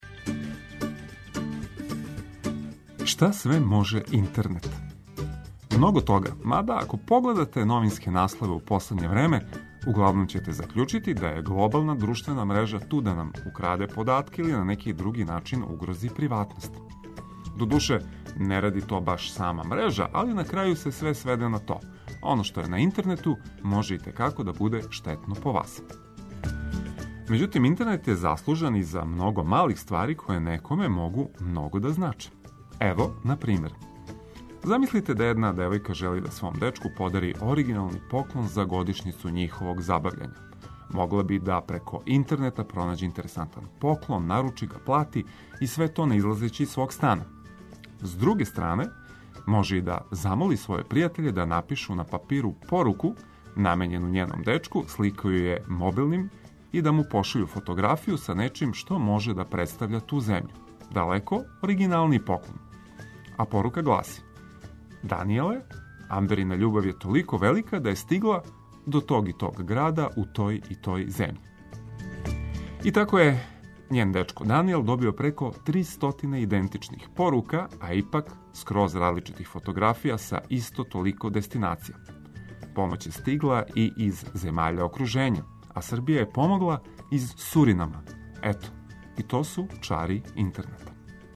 У нашем друштву сазнајте важне иформације ослушкујући блиставе хитове за расањивање.